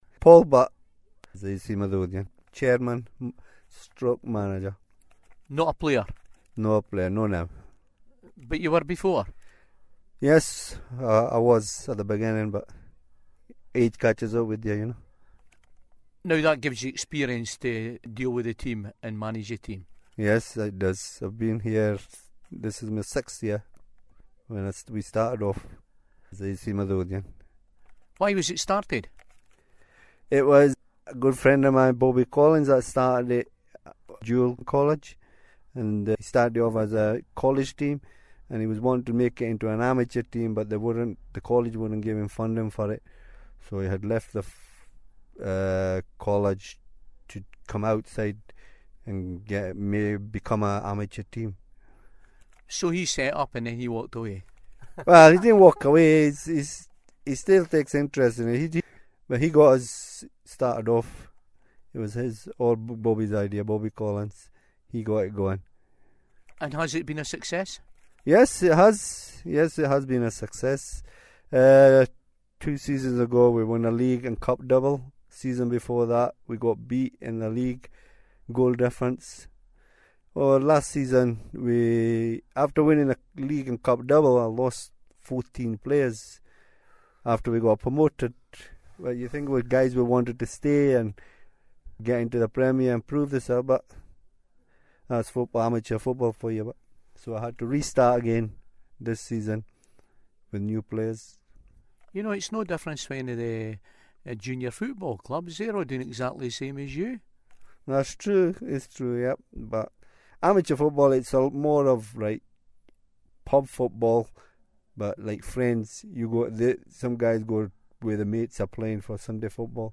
AC Midlothian Pesentation Evening
At the Star Club